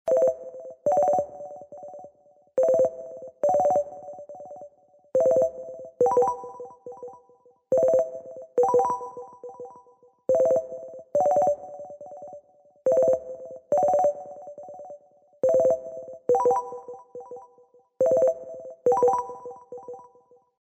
Teams incoming-ringtone-level40.mp3